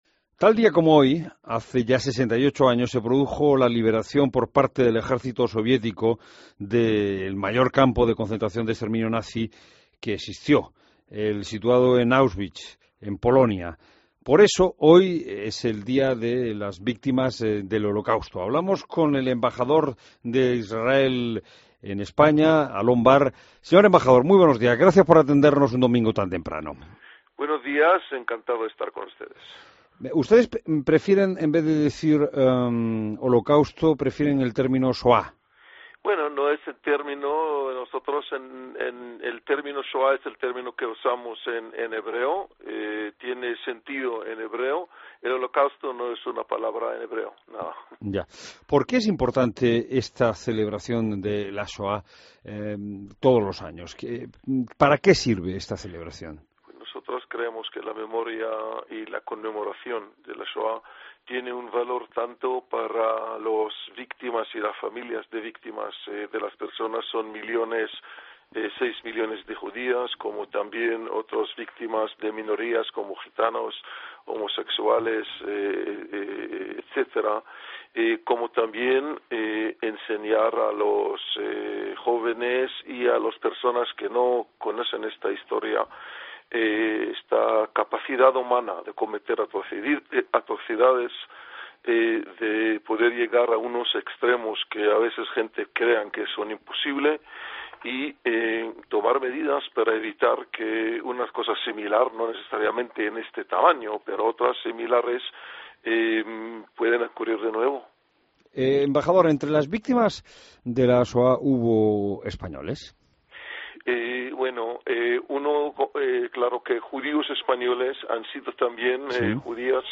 Entrevista al embajador de Israel en España y Andorra, Alon Bar